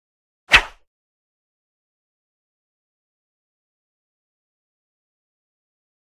Sword: Single Swish; Single, Medium High Pitched, Fast Swish. Close Perspective. Whoosh.